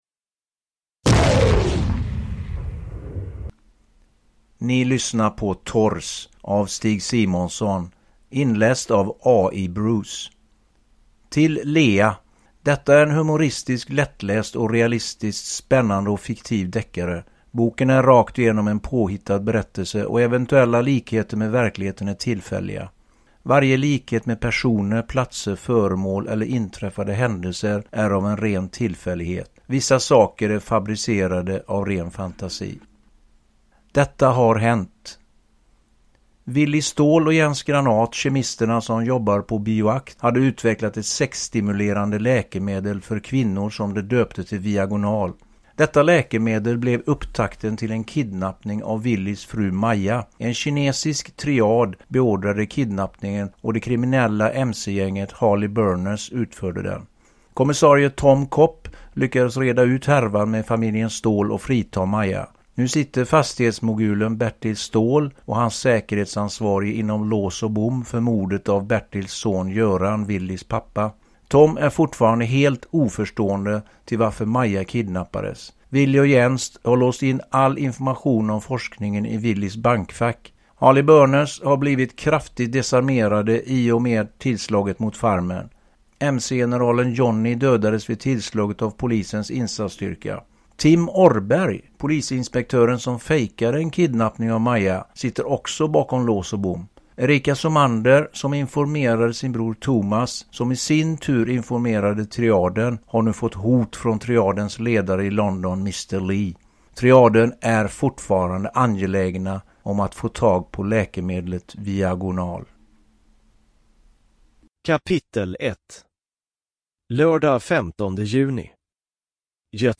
Uppläsare: AI Bruce
Ljudbok